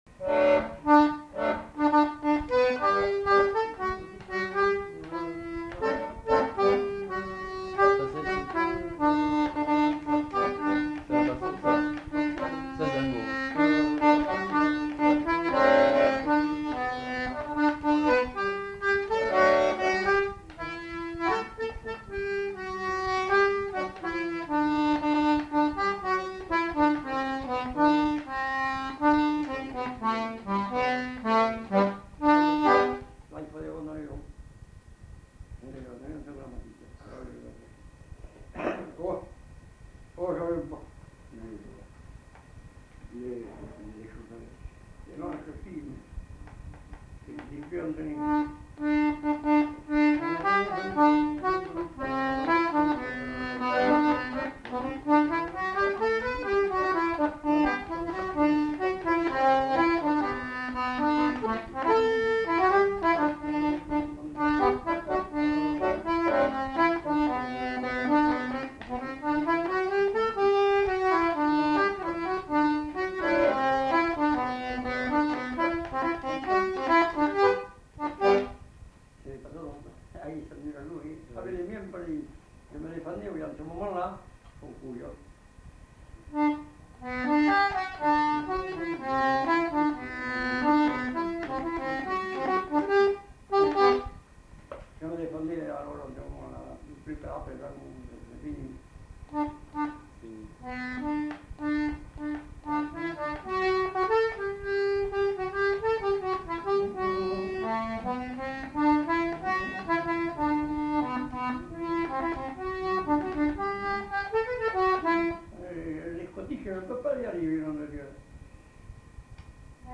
Aire culturelle : Marmandais gascon
Lieu : Mas-d'Agenais (Le)
Genre : morceau instrumental
Instrument de musique : accordéon diatonique
Danse : tango